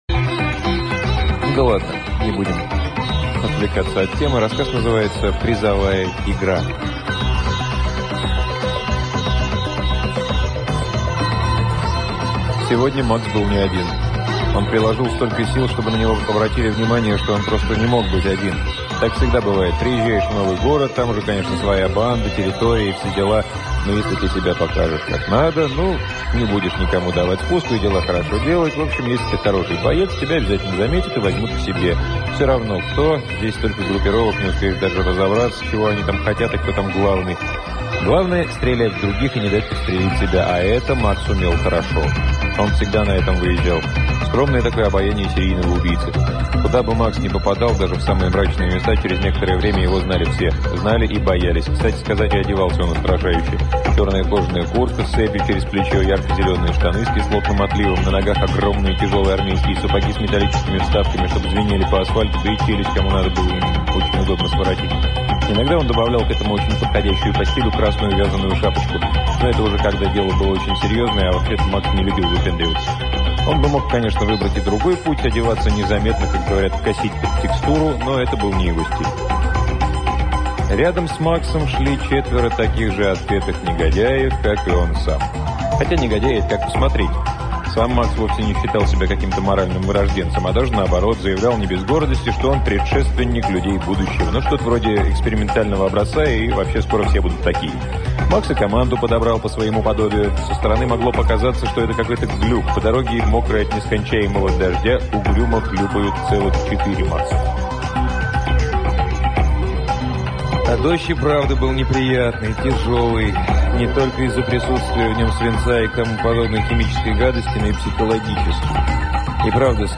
Аудиокнига Алексей Соколовский — Призовая игра